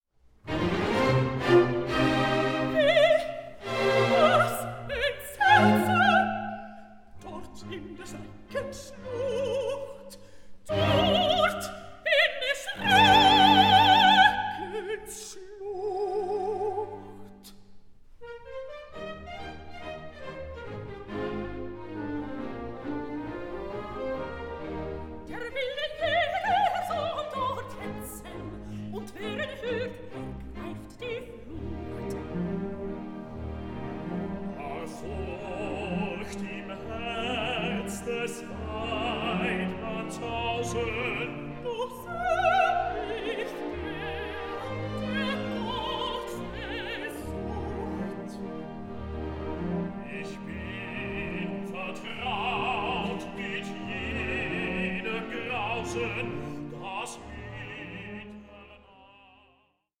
THE GERMAN ROMANTIC OPERA PAR EXCELLENCE